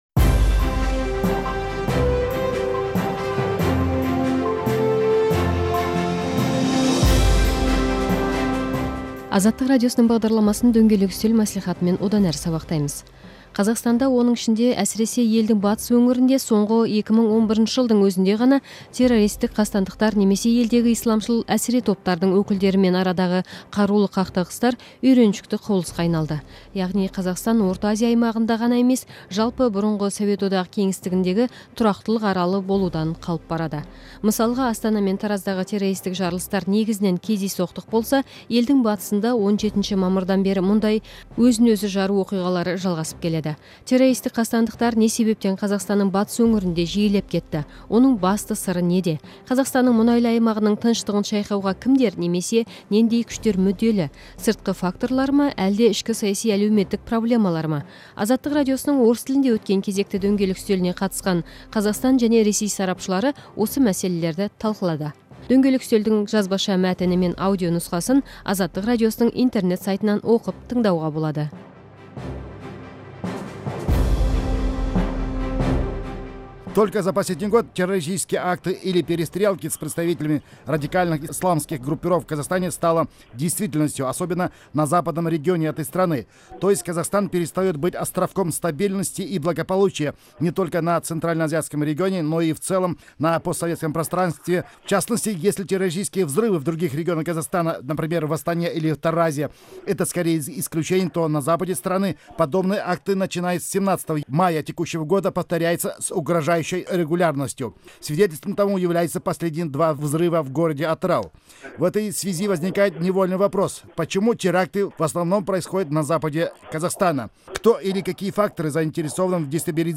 Қазақстандағы жарылыстарға қатысты сұқбатты тыңдаңыз